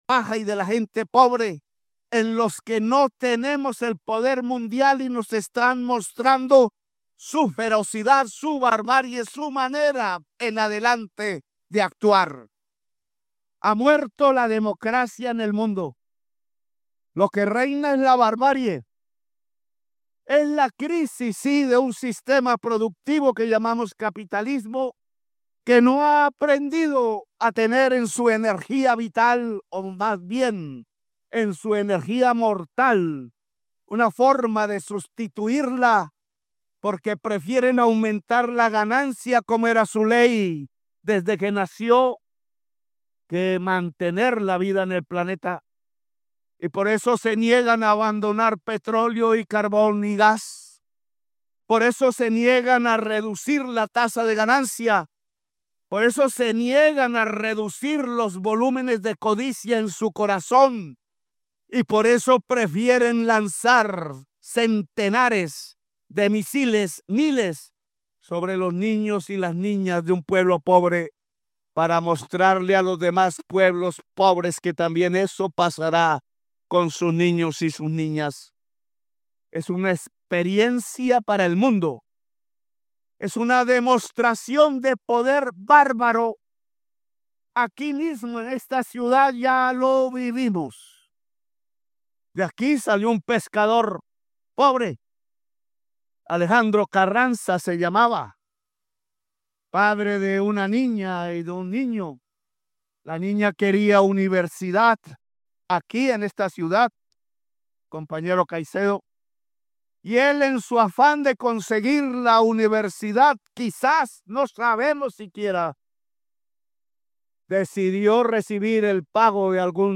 GUSTAVO PETRO, PRESIDENTE DE COLOMBIA
Durante su intervención, en el marco de la CELAC- EU, y de la III Cumbre Social de los Pueblos de América Latina y el Caribe, apuntó directamente contra el presidente estadounidense Donald Trump y el senador Marco Rubio, a quienes tildó de mentirosos y los responsabilizó de justificar ataques contra pueblos pobres bajo falsos argumentos de lucha antidrogas.